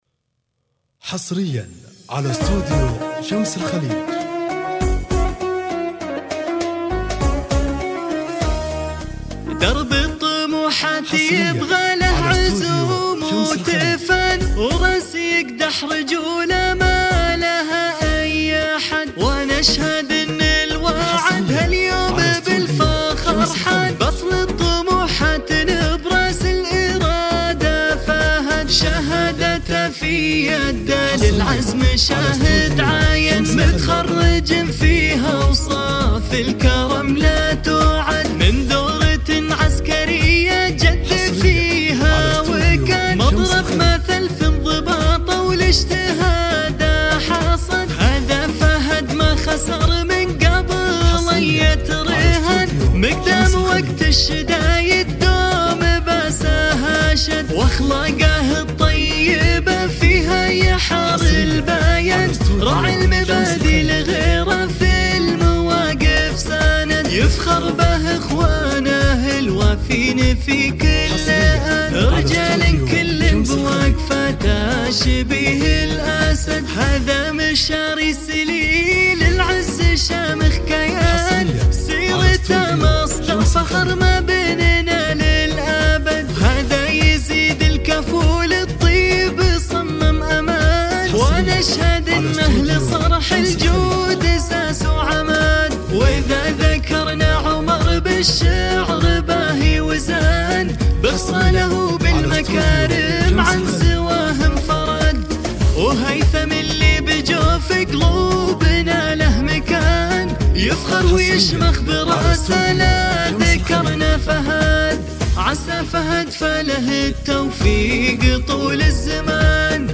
موسيقى تخرج عسكري